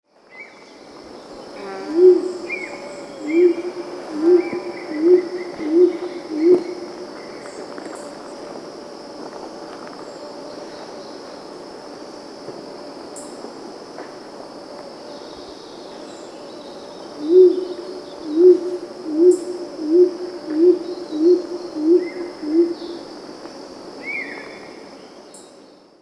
Their distinctive, repeated ‘whoop, whoop’ calls – with a rising inflection delivered at a regular rhythm and easily distinguished from the similar but accelerating ‘whp-whoo’ of the related and equally gorgeous Rose-crowned Fruit-Dove – are a very characteristic sound of northeastern rainforests.
Superb Fruit Dove (Ptilinopus superbus)